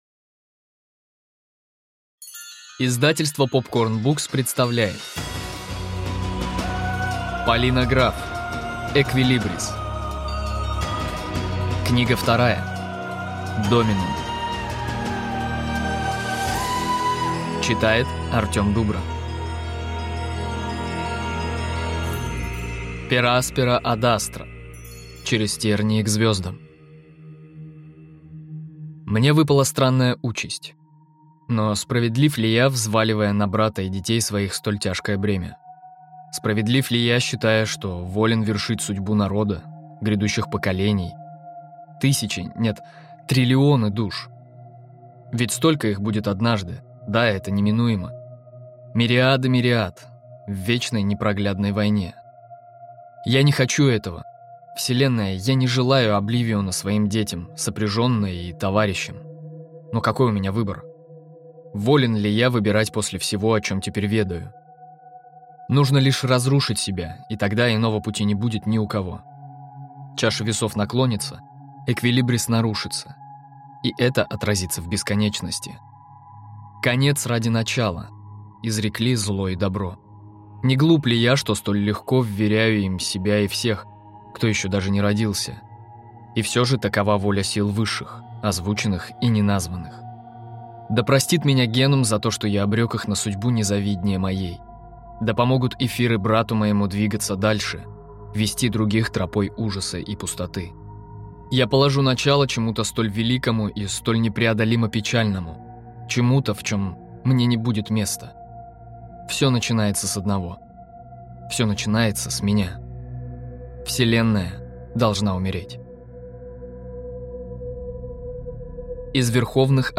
Аудиокнига Доминум | Библиотека аудиокниг